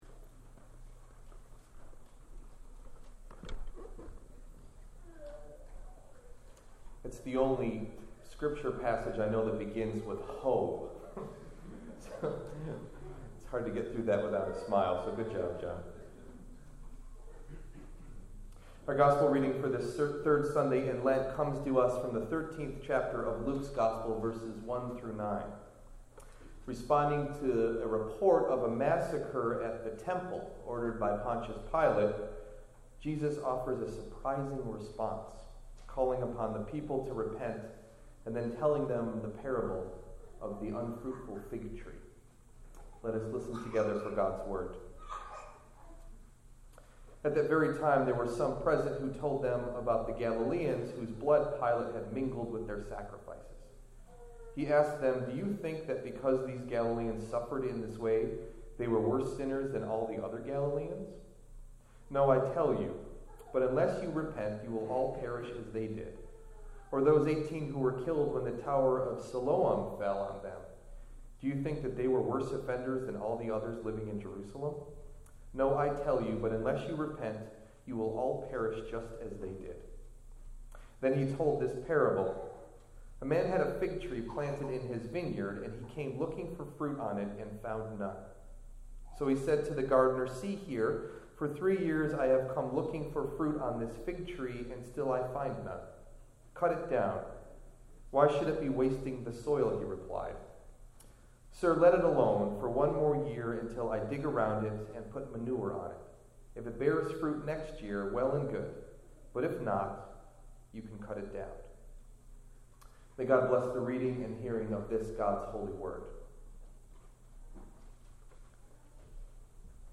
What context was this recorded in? Delivered at: The United Church of Underhill